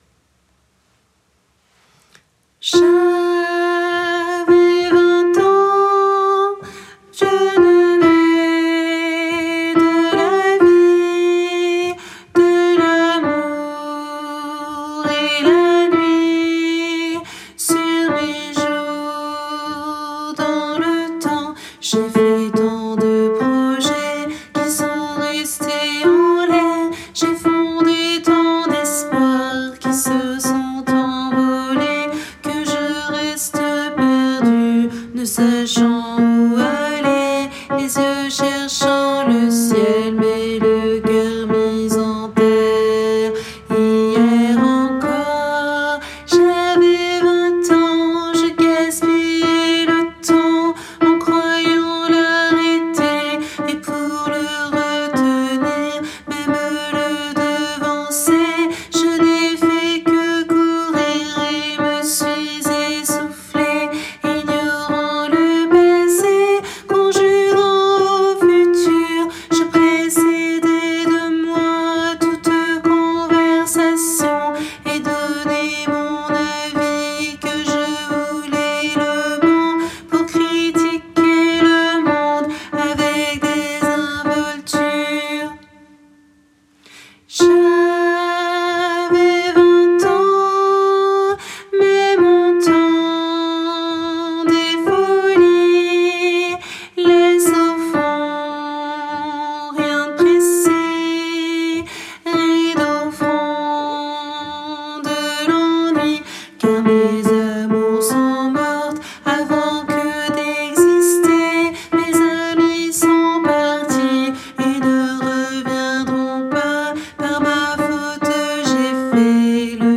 - Chant pour chœur mixte à 3 voix (SAH)
MP3 versions chantées
Hommes